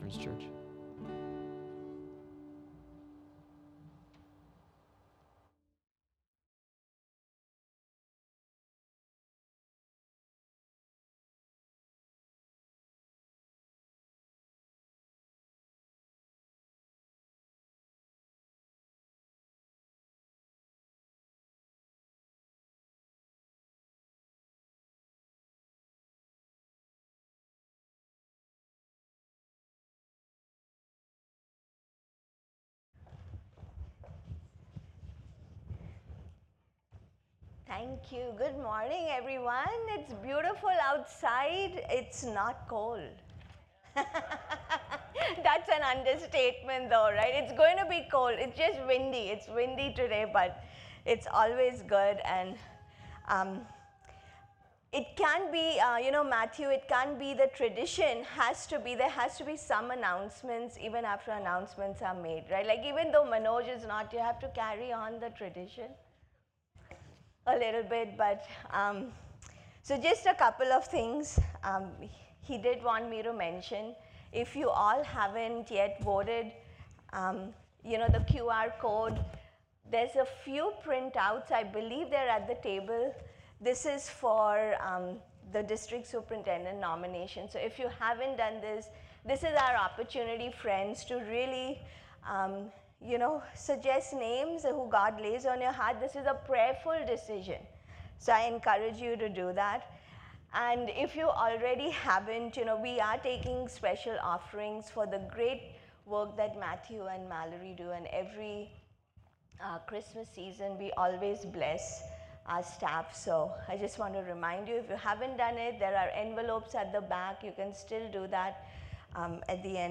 December 1st, 2024 - Sunday Service - Wasilla Lake Church